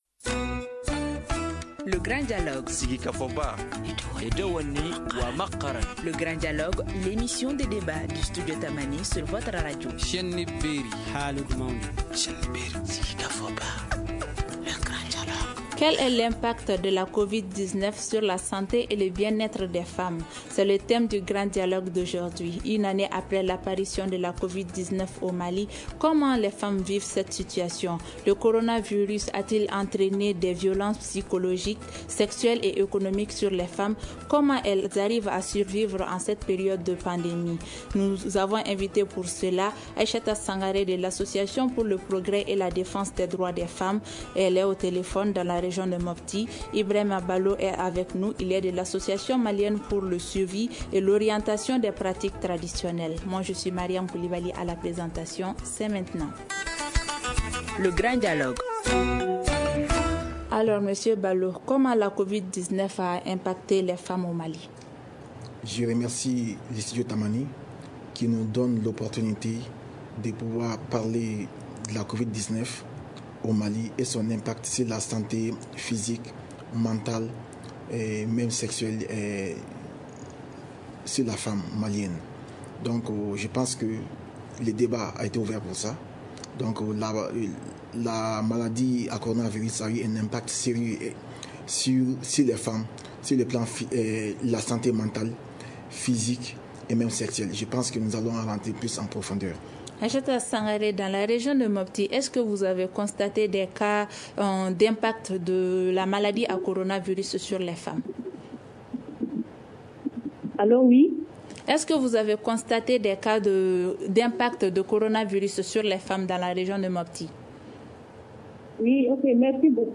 Le coronavirus a-t-il entraîné plus de violences physiques, sexuelles et économiques sur les femmes ? Comment gèrent-elles leurs vies avec cette épidémie ?Le Grand Dialogue de Studio Tamani pose le débat sur ces questions en partenariat avec le programme d’engagement civique.